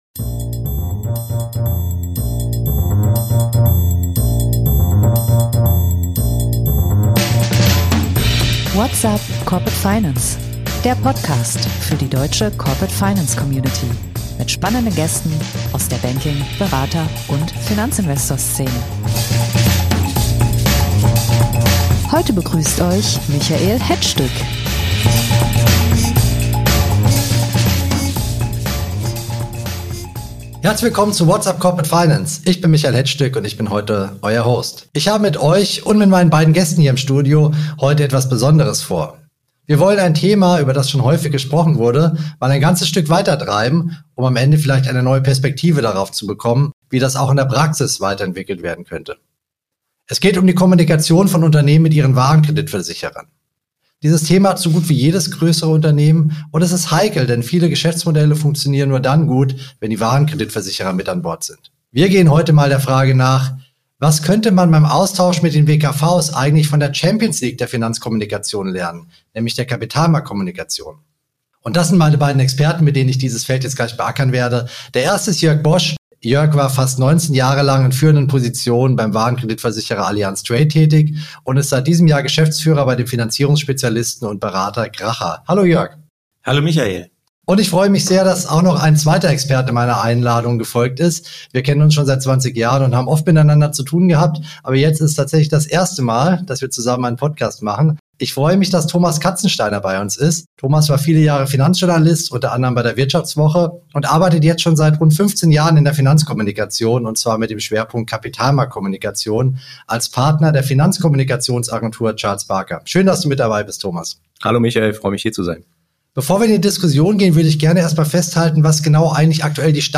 Was könnten sich CFOs in der Kommunikation mit ihren Warenkreditversicherern von der Champions League der Finanzkommunikation abschauen, der Kapitalmarktkommunikation? Das diskutieren zwei Profis aus beiden Bereichen in dieser Episode.